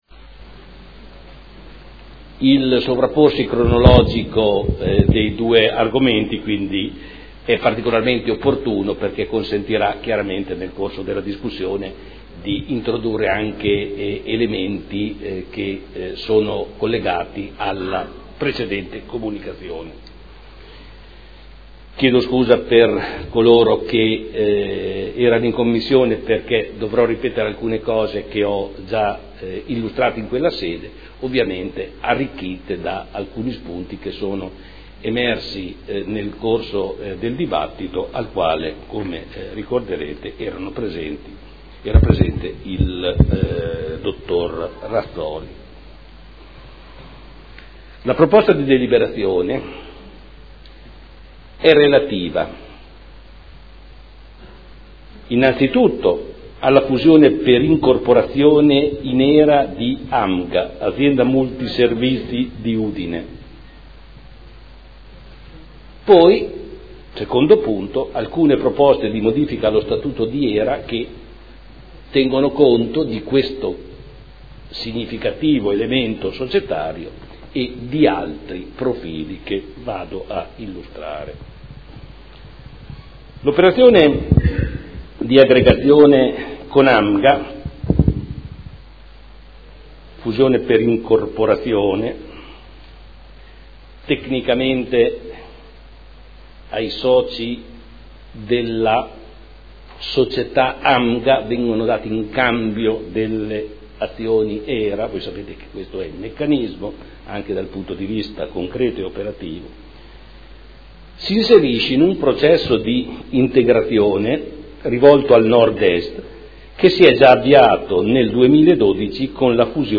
Giorgio Pighi — Sito Audio Consiglio Comunale
Seduta del 24 marzo. Proposta di deliberazione: Fusione per incorporazione di AMGA Azienda Multiservizi di Udine in Hera e modifiche allo Statuto Hera